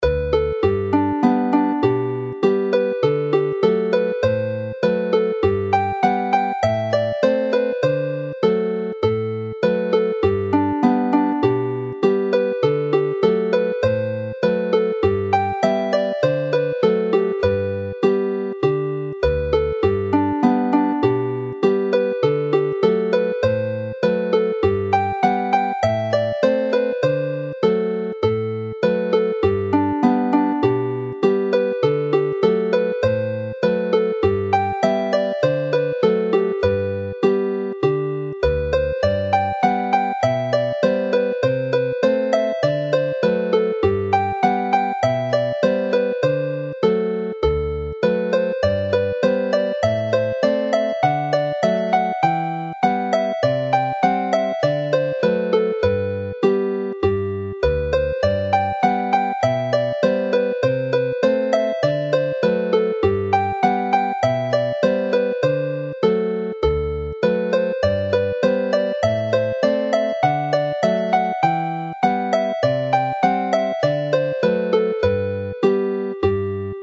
Glasgow hornpipe - as a reel
As well as their names, they have melodies which are very cheerful and follow each other well in the set.
They are all played as reels in this set; next monthe they will be presented with theh same scoring but played as hornpipes, with a skipping pulse.
Play the tune slowly